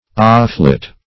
offlet - definition of offlet - synonyms, pronunciation, spelling from Free Dictionary Search Result for " offlet" : The Collaborative International Dictionary of English v.0.48: Offlet \Off"let\, n. [Off + let.] A pipe to let off water.